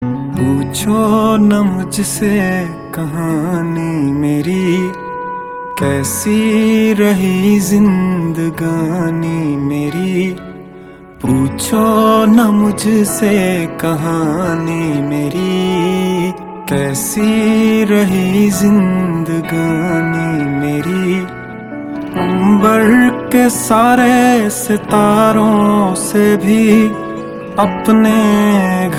heartfelt lyrics
soulful vocals